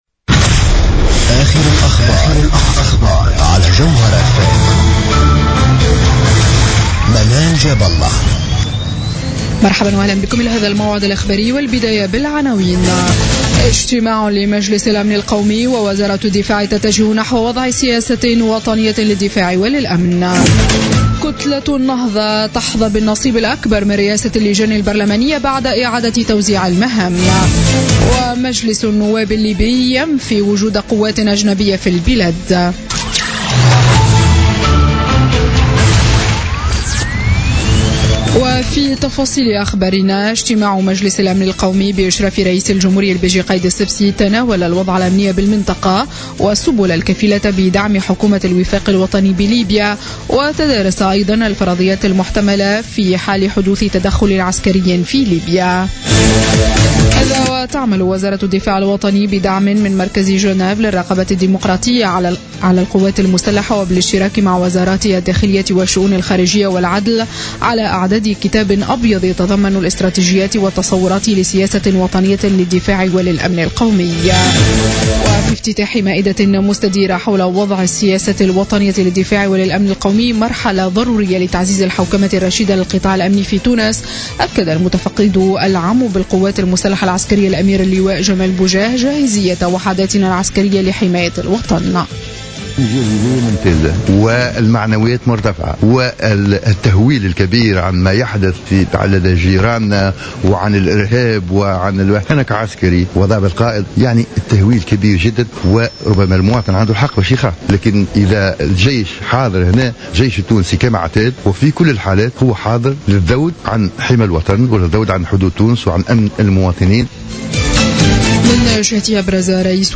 نشرة أخبارمنتصف الليل ليوم الجمعة 26 فيفري 2016